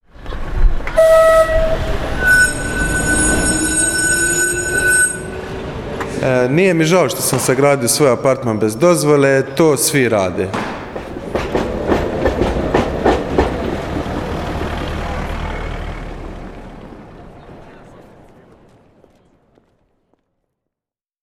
radio intervention
STATEMENTS BROADCASTED ON RADIO "SLJEME":